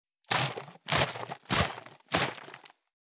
343ペットボトル,ワインボトルを振る,水の音,ジュースを振る,シャカシャカ,カシャカシャ,チャプチャプ,ポチャポチャ,シャバシャバ,チャプン,ドボドボ,グビグビ,
ペットボトル